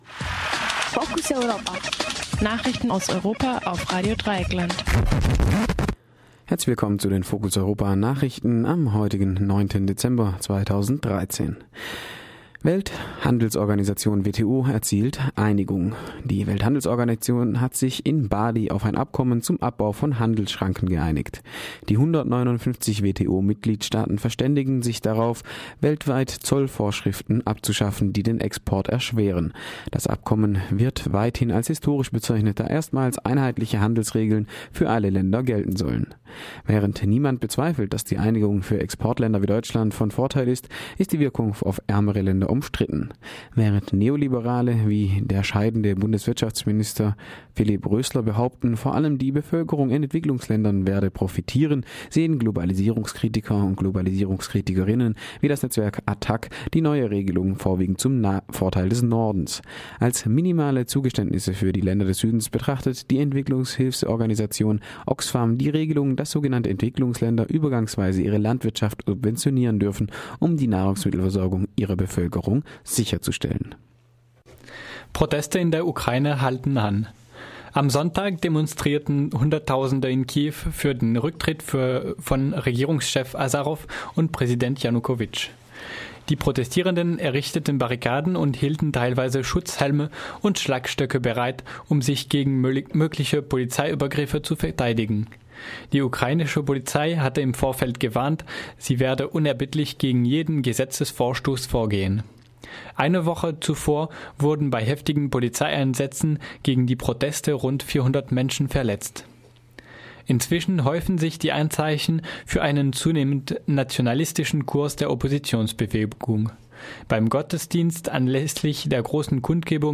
Focus Europa Nachrichten vom Montag, den 9. Dezember - 9.30 Uhr